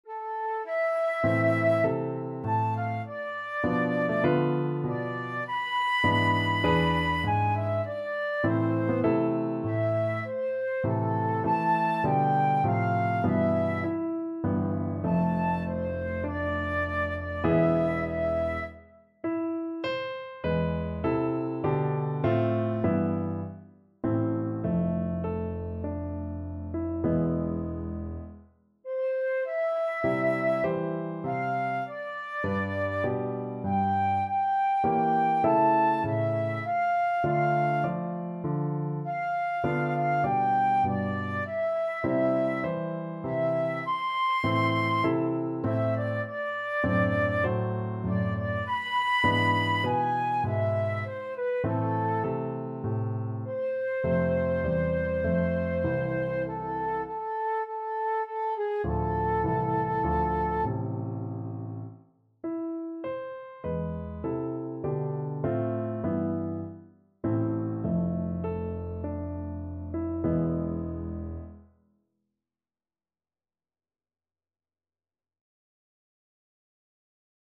Flute
A minor (Sounding Pitch) (View more A minor Music for Flute )
Largo =c.100
4/4 (View more 4/4 Music)
Classical (View more Classical Flute Music)